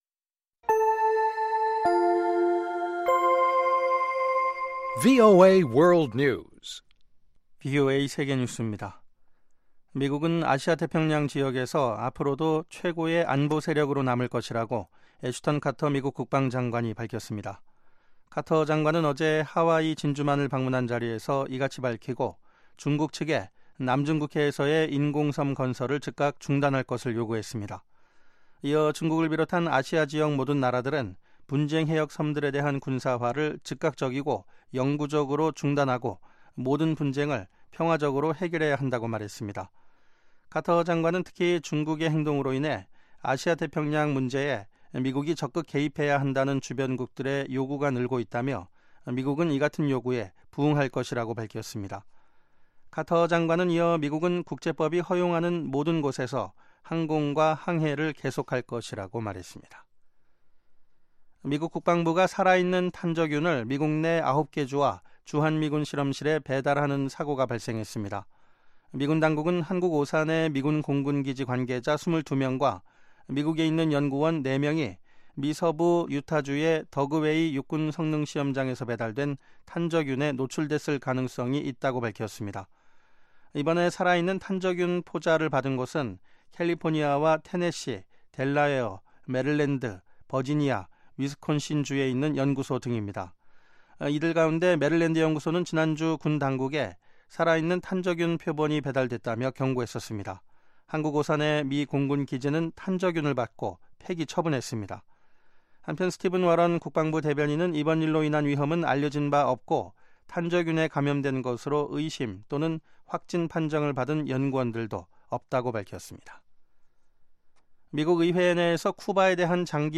VOA 한국어 방송의 간판 뉴스 프로그램 '뉴스 투데이' 2부입니다.